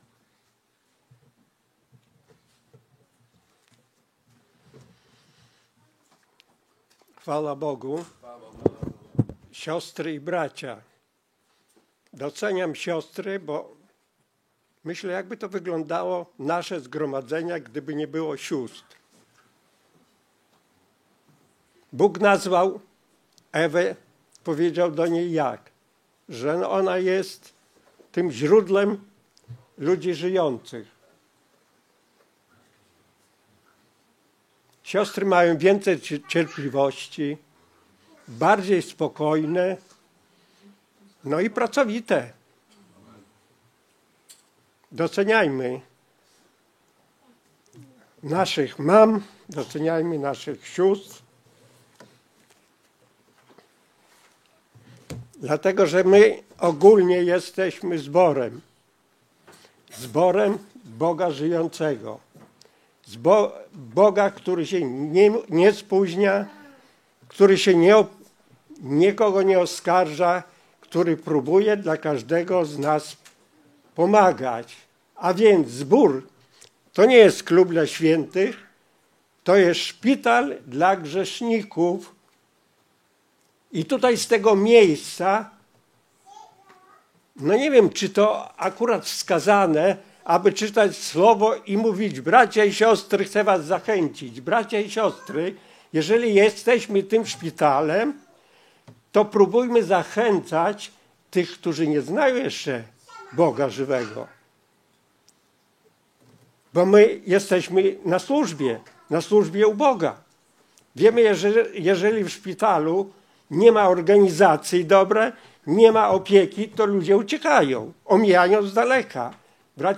Skorzystaj z przycisku poniżej, aby pobrać kazanie na swoje urządzenie i móc słuchać Słowa Bożego bez połączenia z internetem.